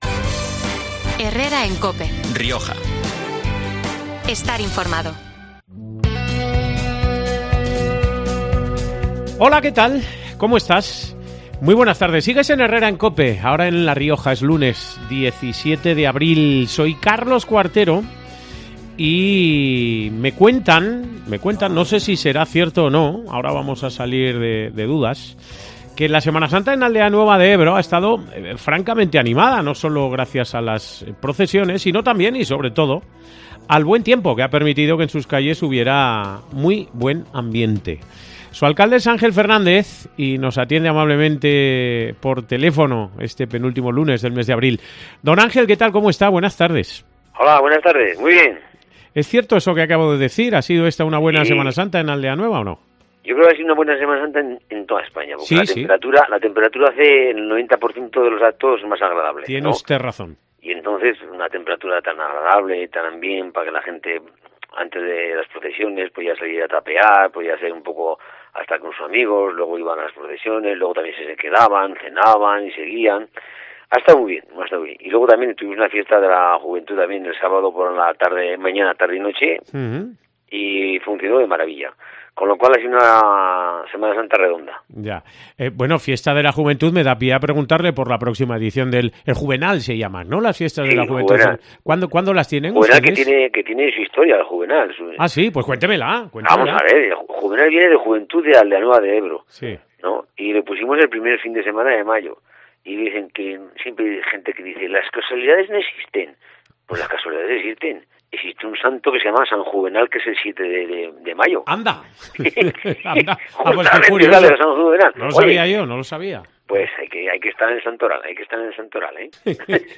El alcalde de Aldeanueva de Ebro ha repasado la actualidad de su municipio en COPE Rioja este 17 de abril.